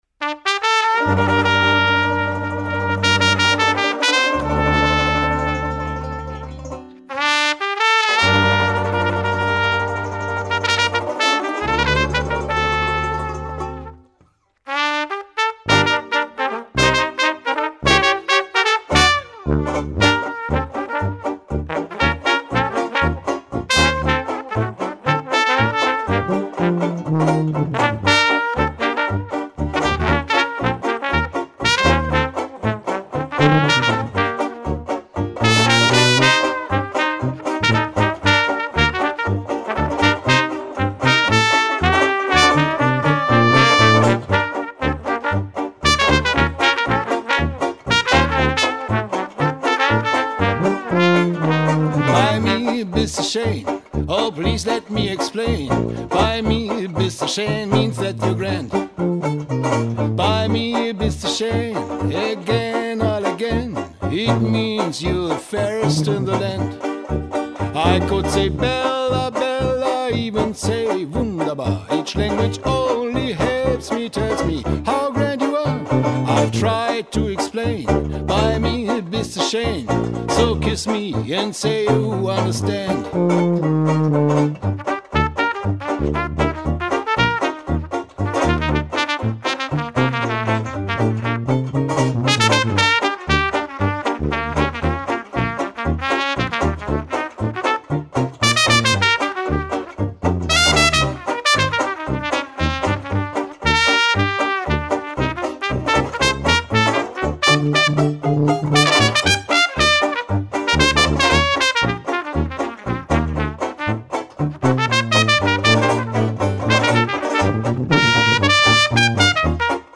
Demo Songs